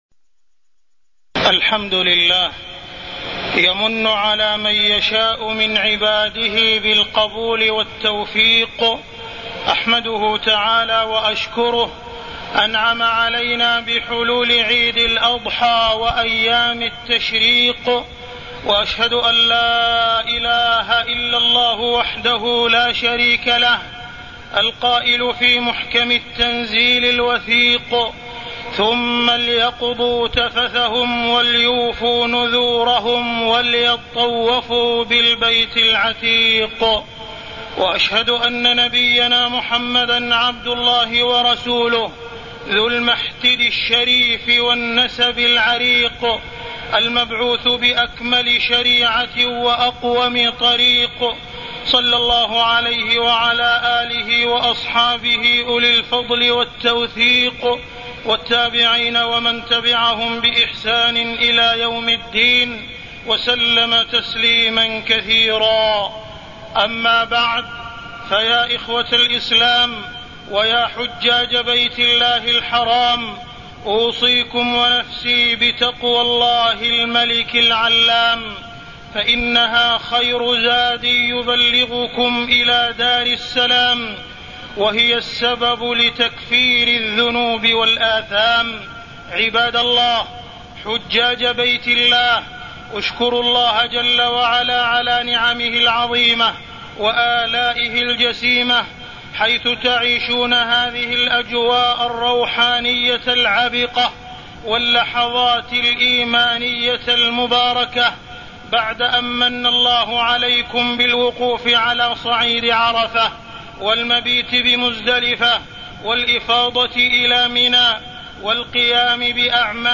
تاريخ النشر ١١ ذو الحجة ١٤٢٠ هـ المكان: المسجد الحرام الشيخ: معالي الشيخ أ.د. عبدالرحمن بن عبدالعزيز السديس معالي الشيخ أ.د. عبدالرحمن بن عبدالعزيز السديس واذكروا الله في أيام معدودات